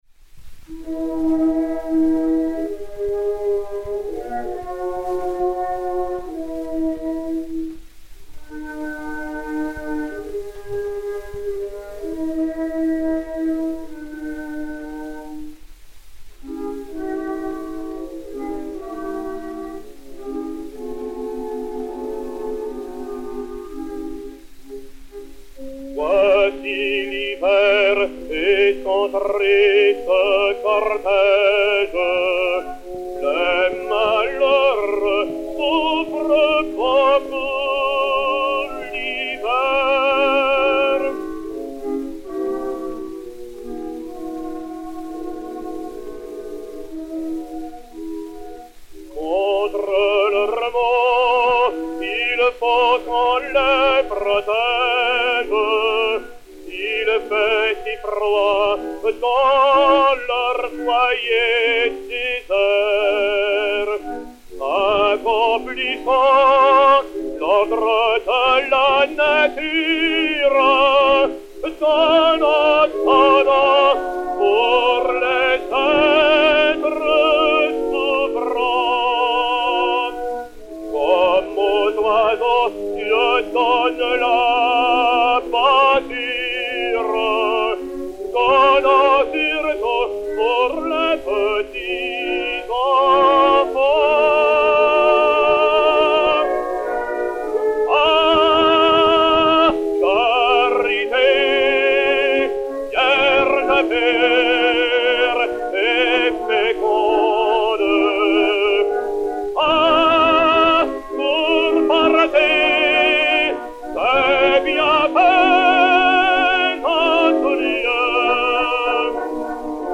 Orchestre
enr. à Paris en 1905